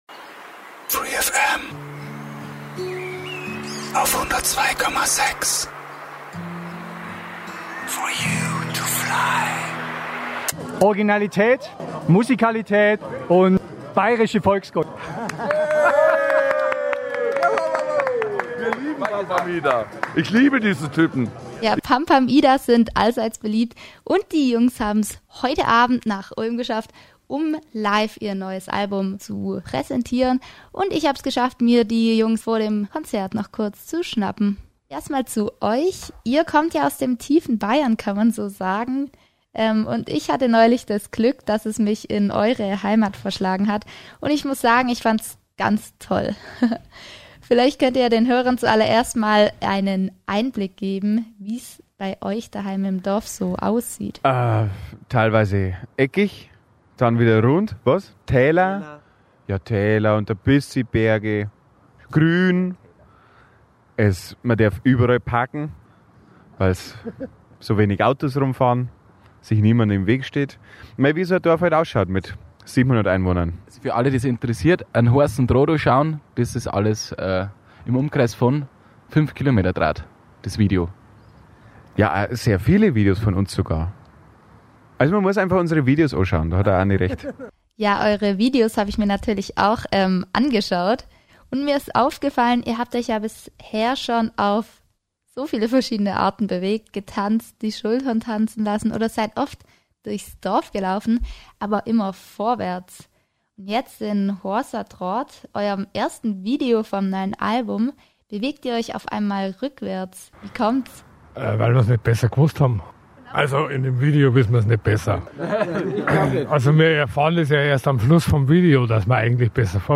Pam Pam Ida im Interview